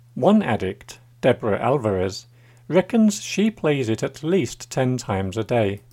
DICTATION 2